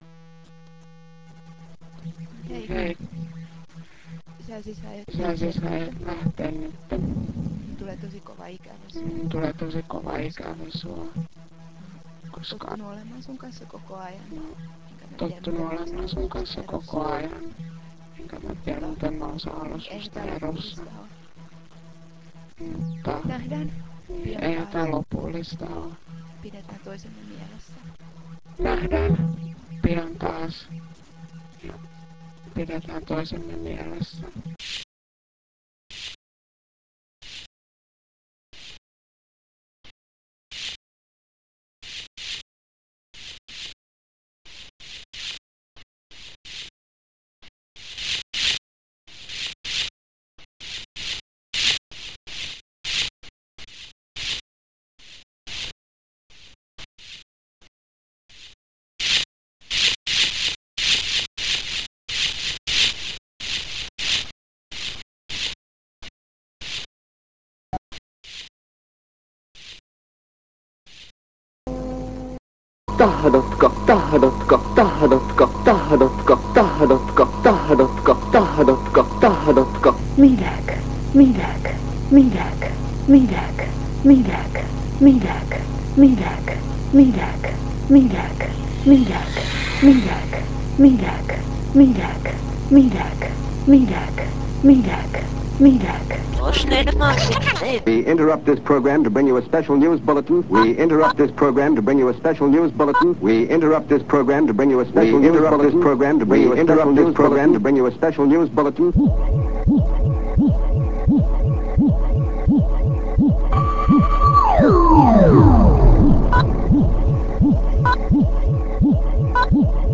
- nordii eerotik soundscape | äänimaisema
live digiconcert